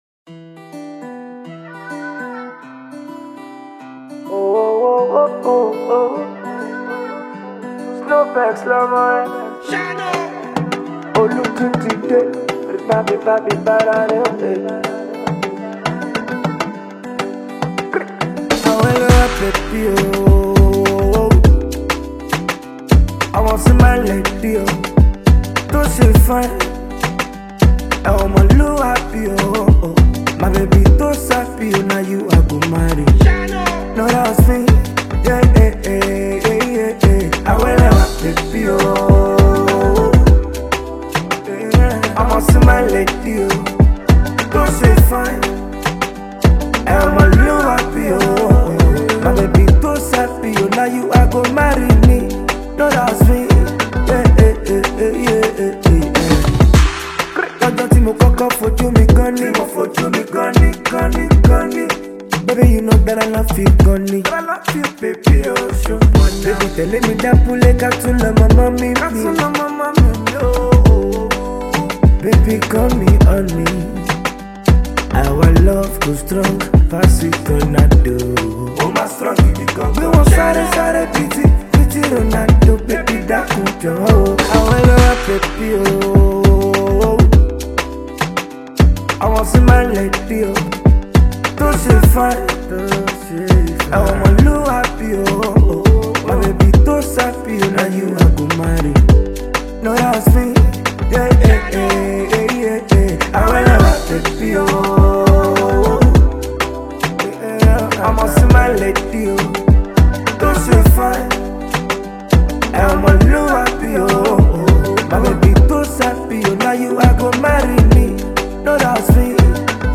This tune is made with melodious rhythm and vibes